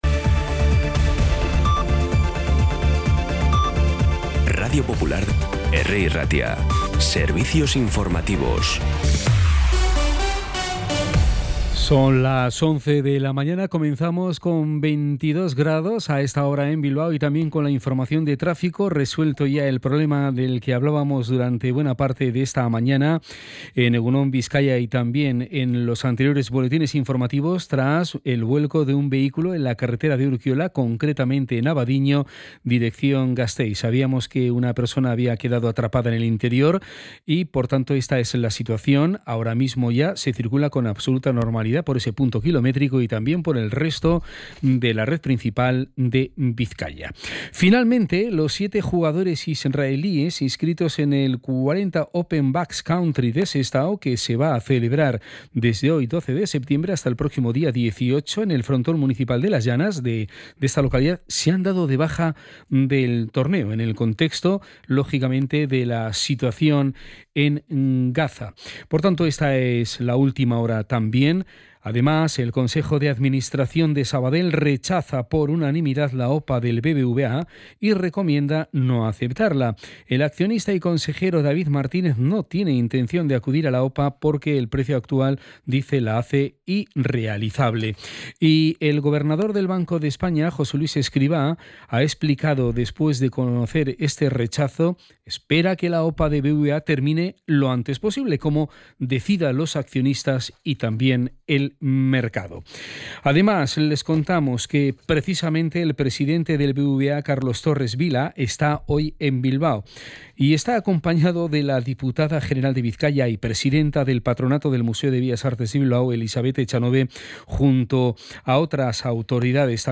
Podcast Informativos
Los titulares actualizados con las voces del día.